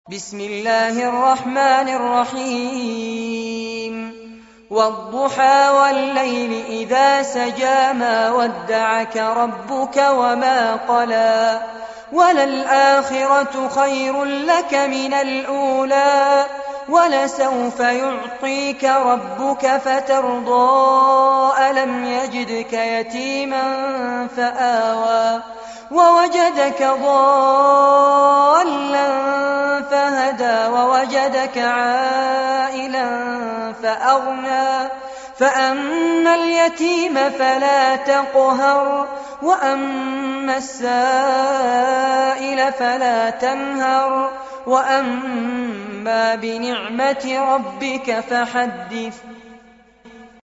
عنوان المادة سورة الضحى- المصحف المرتل كاملاً لفضيلة الشيخ فارس عباد جودة عالية